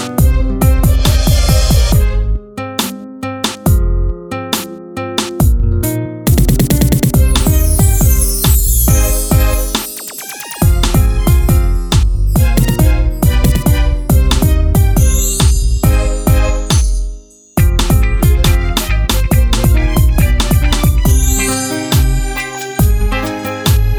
no Backing Vocals R'n'B / Hip Hop 4:32 Buy £1.50